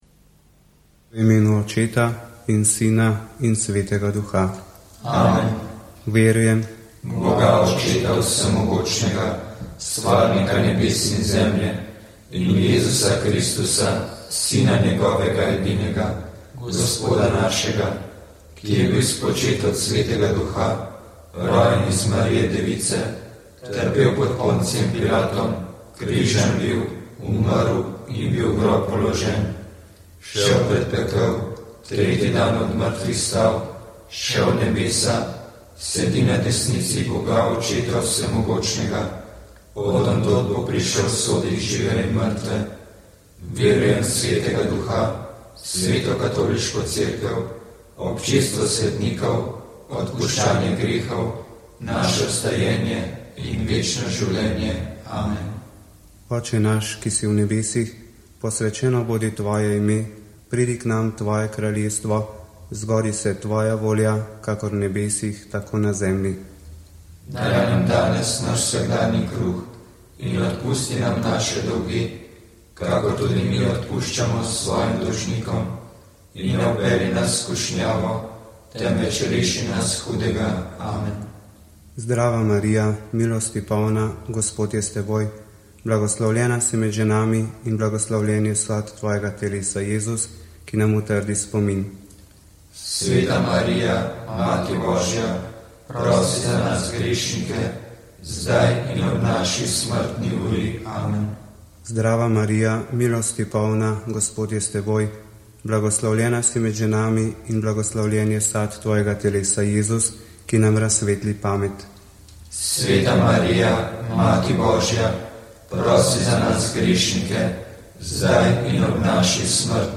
Molili so bogoslovci.